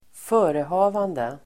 Ladda ner uttalet
Folkets service: förehavande förehavande substantiv, doings , activities Uttal: [²f'ö:reha:vande] Böjningar: förehavandet, förehavanden, förehavandena Synonymer: aktivitet, göromål, syssla Definition: sysselsättning